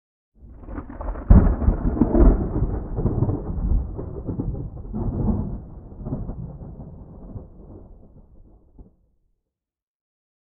thunderfar_19.ogg